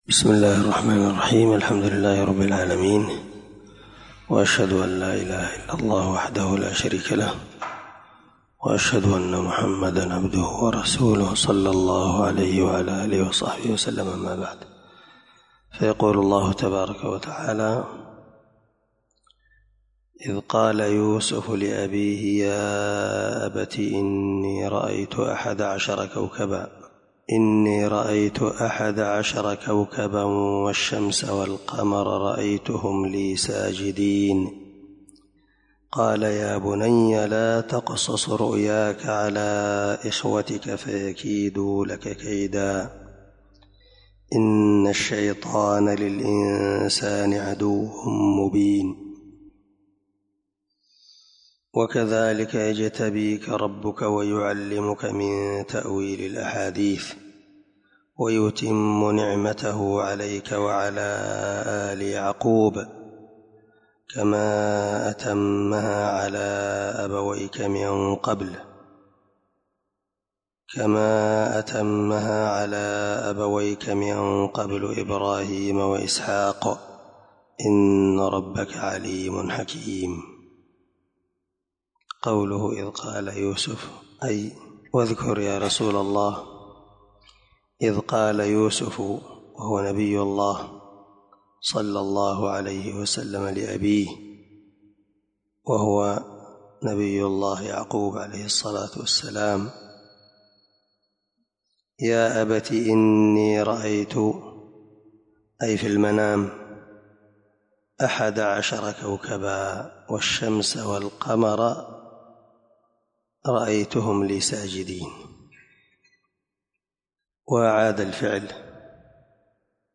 652تفسير السعدي الدرس2 آية(4_6) من سورة يوسف من تفسير القرآن الكريم مع قراءة لتفسير السعدي
دار الحديث- المَحاوِلة- الصبيحة.